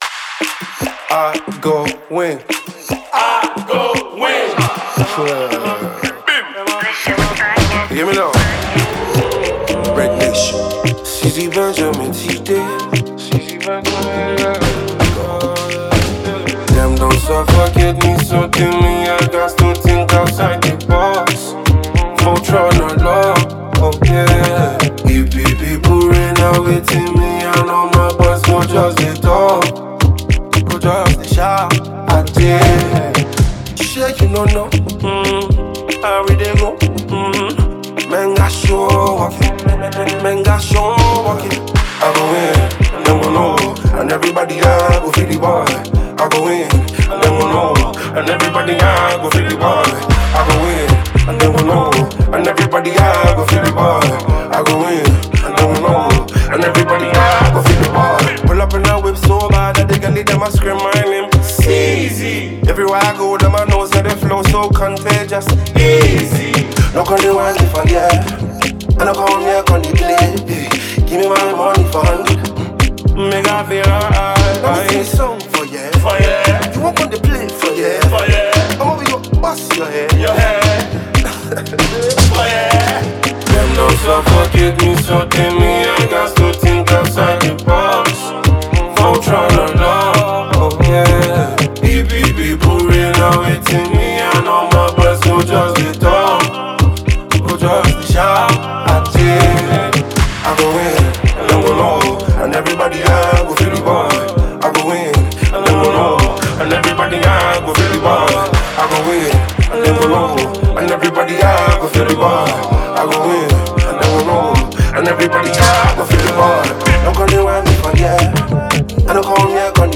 an uplifting anthem about perseverance and triumph.
With its catchy beat and inspiring message